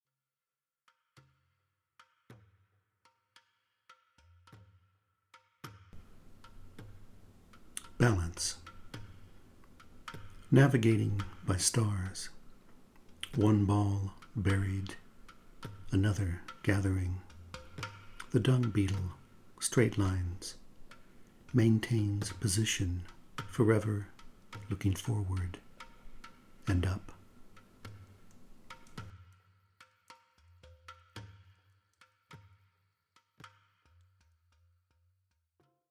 balance-with-music.mp3